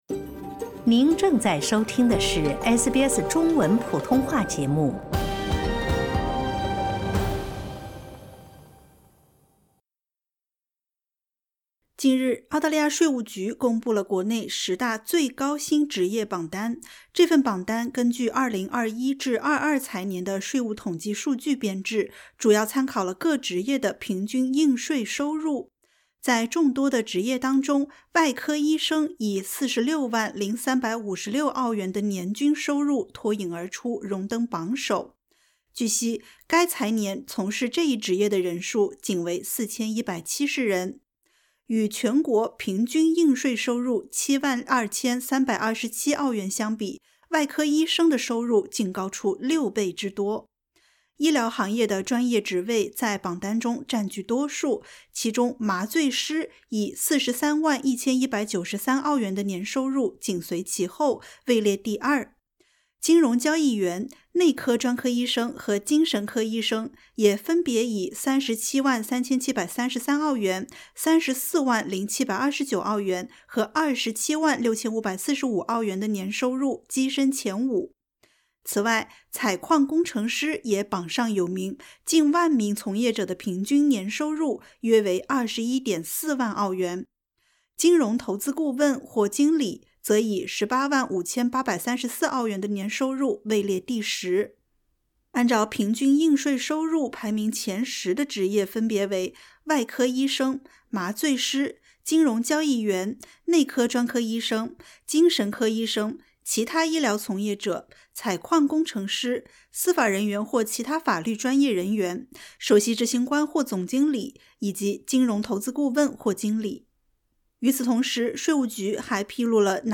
近日，澳大利亚税务局（ATO）公布了国内十大最高薪职业榜单，外科医生荣登榜首。点击 ▶ 收听完整报道。